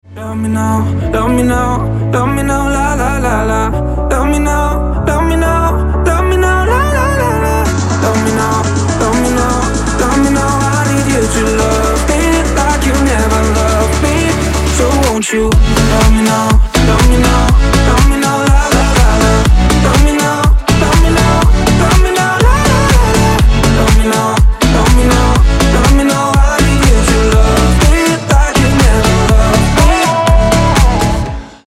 красивый мужской голос
нарастающие
Стиль: house